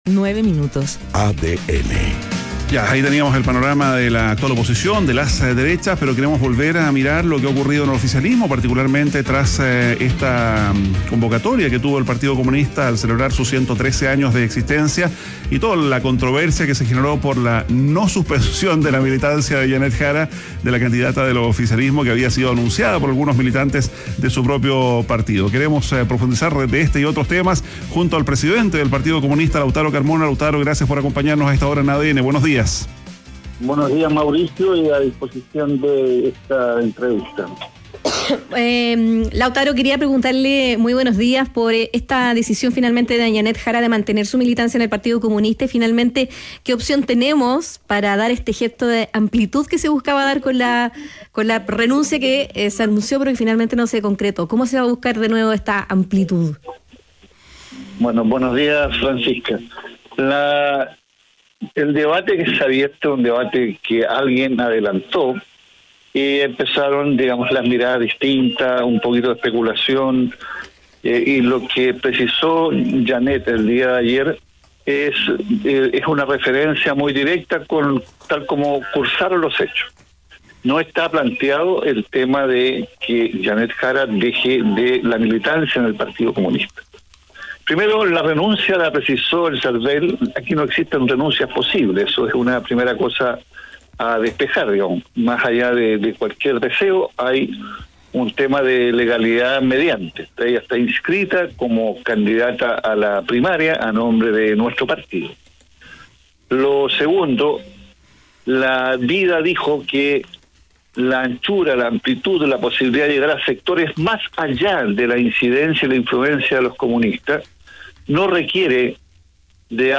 Entrevista a Lautaro Carmona, presidente del Partido Comunista (PC) - ADN Hoy